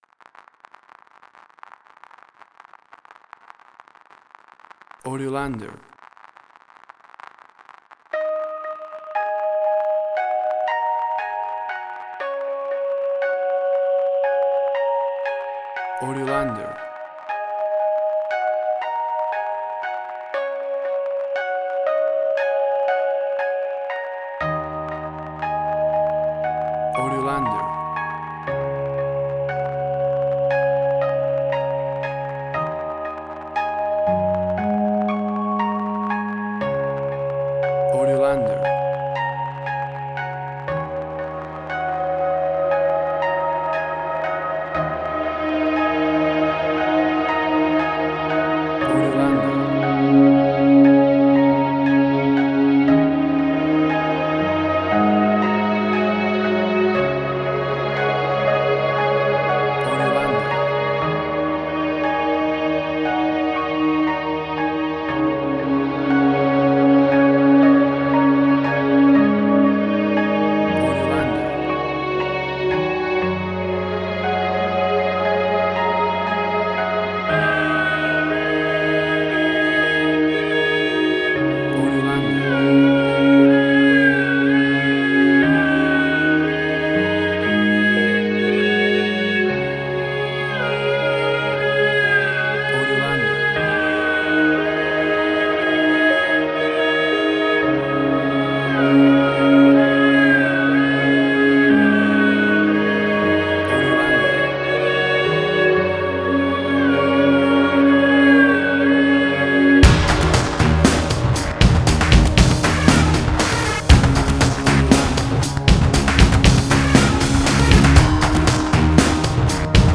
Cinematic Industrial Sci-fi
Tempo (BPM): 60